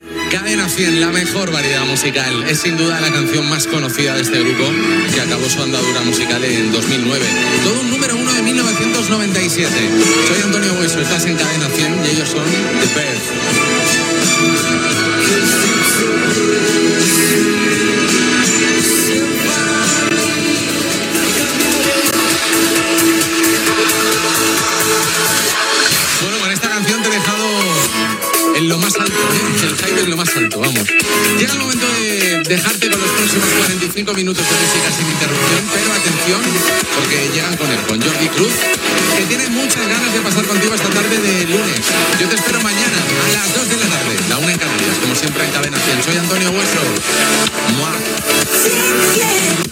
Identificació de la ràdio i del locutor, presentació d'un tema musical, dona pas al següent locutor, comiat i indicatiu
Musical
FM